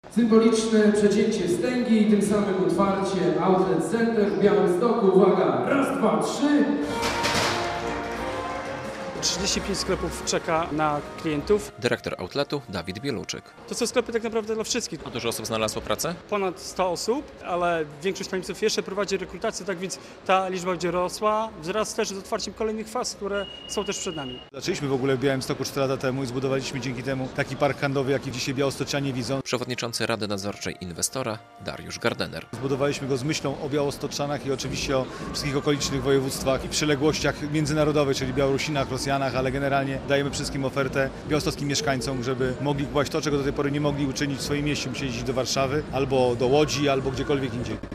Nowy outlet w Białymstoku - relacja